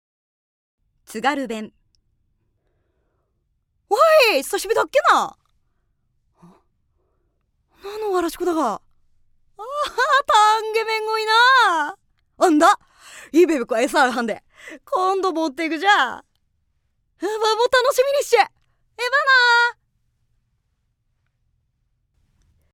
◆津軽弁(おばさん)◆